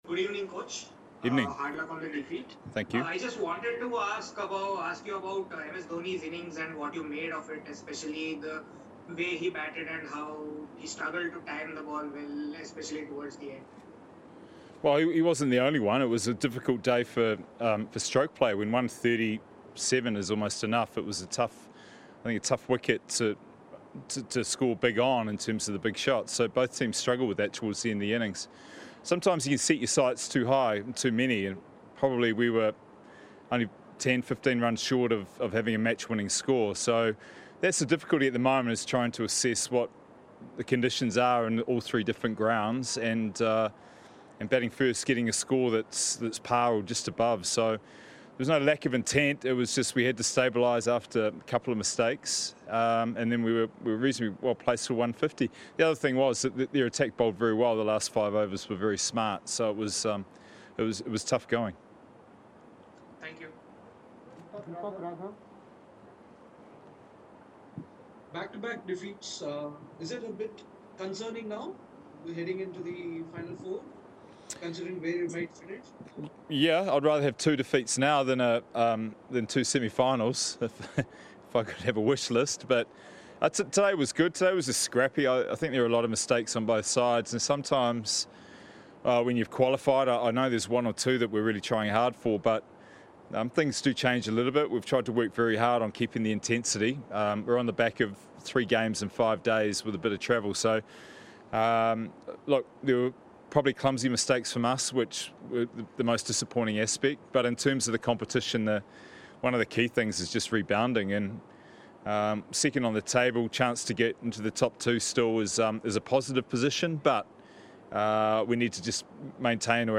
Stephen Fleming, Head Coach of Chennai Super Kings addressed the media at the end of the game